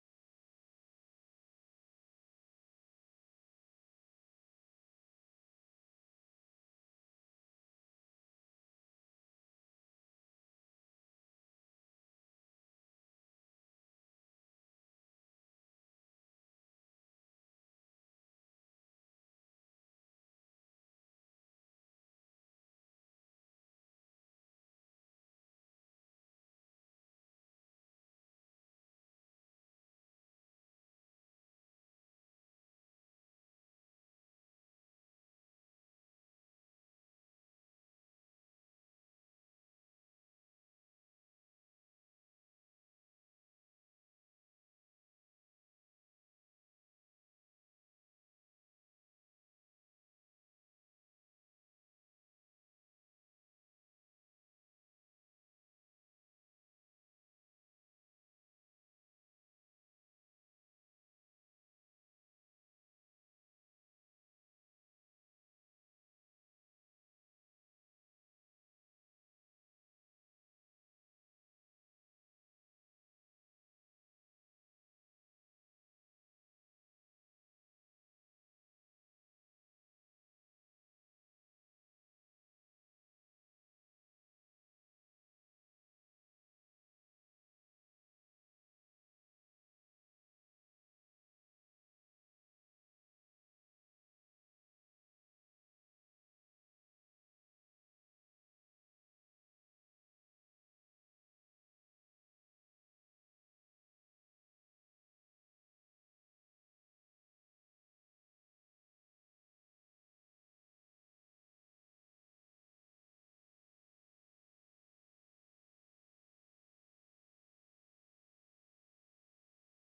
Walking with the Spirit | Sermon | Grace Bible Church